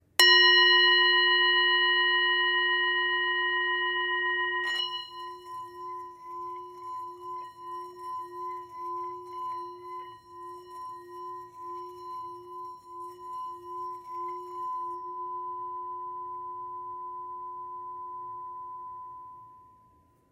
Tepaná tibetská mísa Pemba o hmotnosti 252 g, včetně paličky.
Způsob provedení mísy: Tepaná
tibetska_misa_m40.mp3